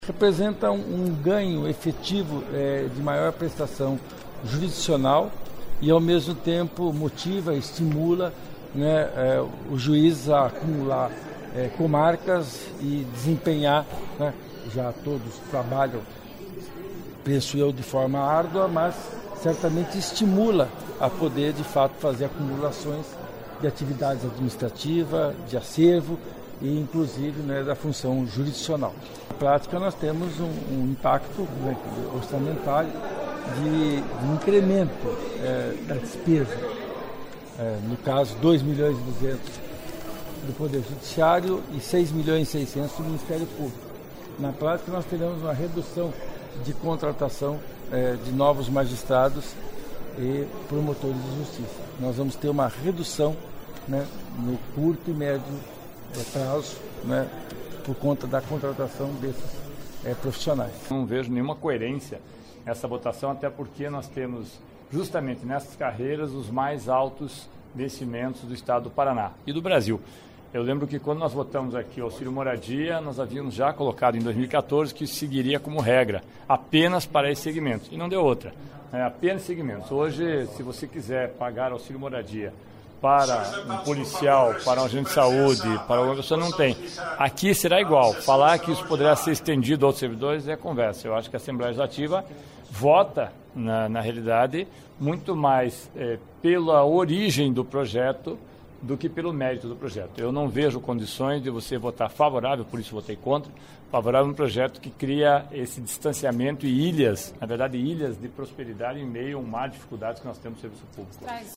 Líder do Governo, deputado Luiz Cláudio Romanelli (PSB) defende aprovação de projetos que estabelece gratificação a magistrados, promotores e defensores públicos. Ouça a entrevista.
(Sonora)